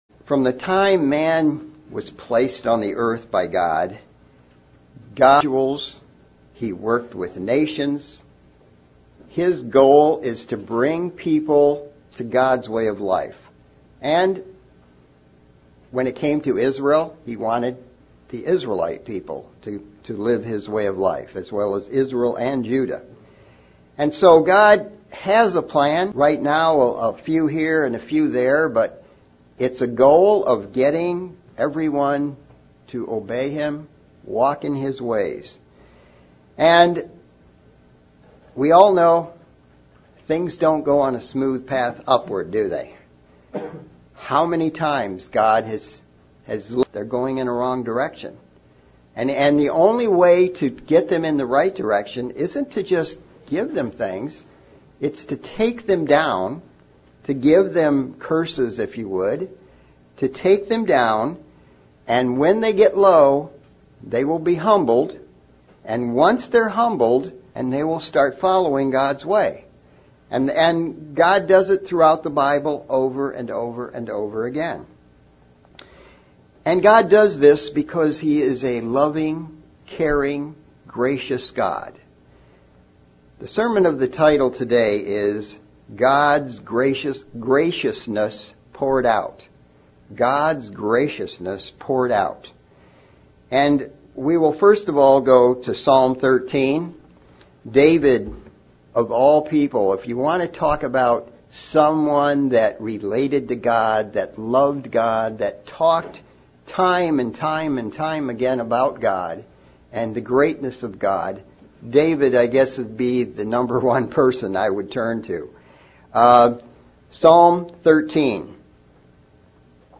Sermon looking at the subject of God's graciousness and how he pours it out on his people and his mercy towards us